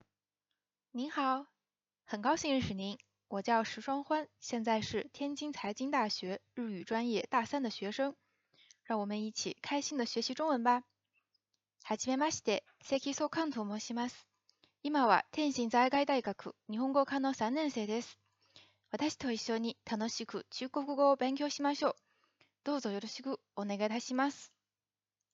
録音放送
自己紹介：